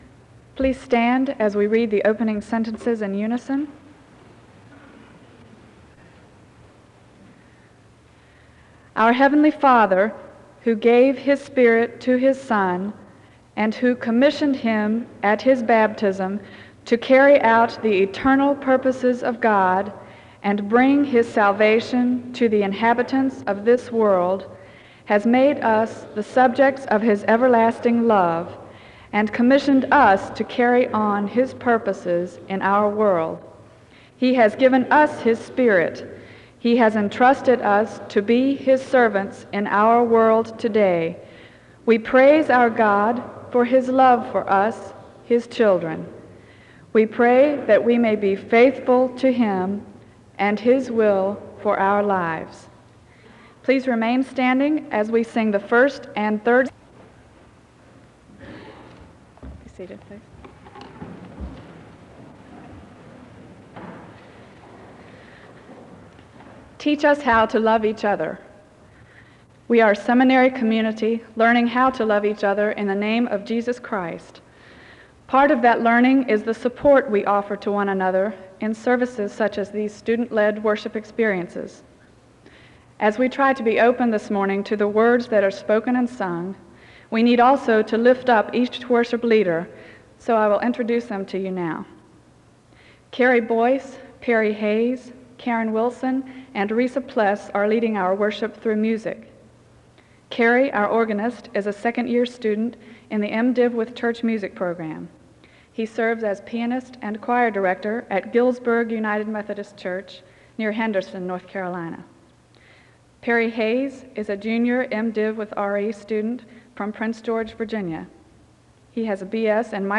SEBTS_Chapel_Student_Revival_1983-04-20.wav